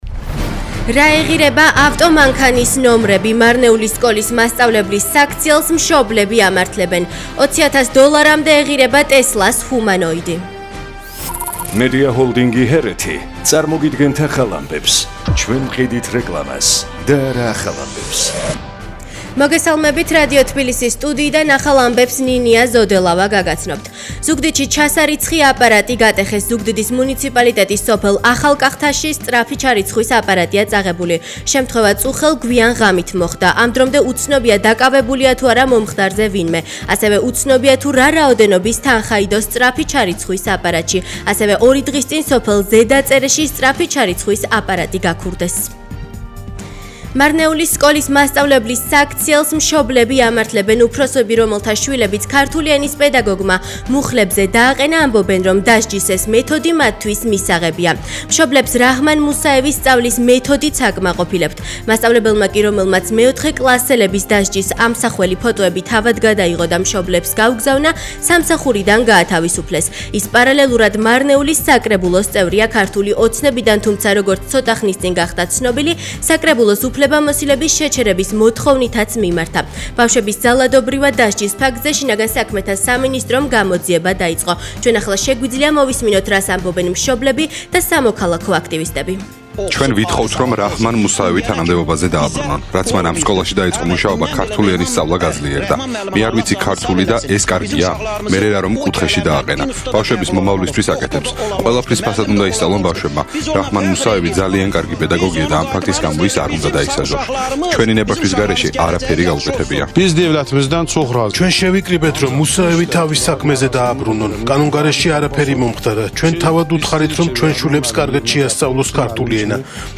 ახალი ამბები 16:00 საათზე